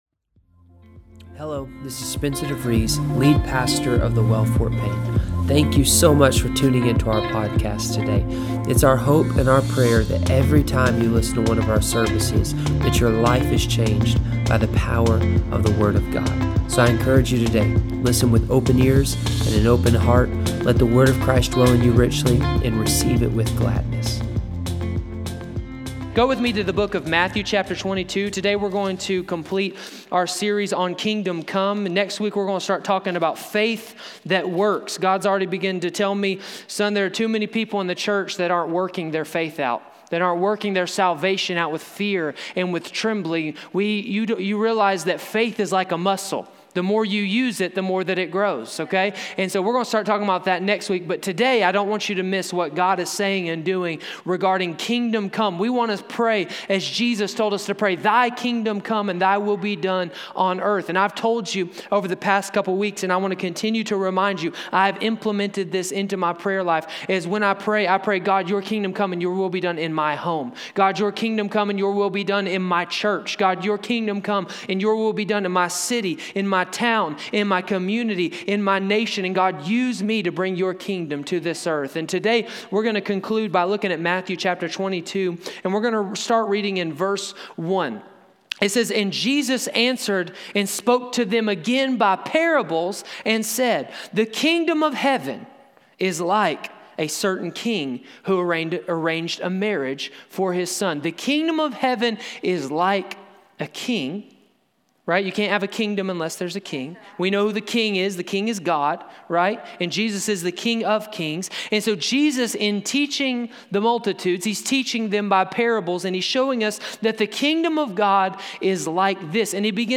Sermons | The Well Fort Payne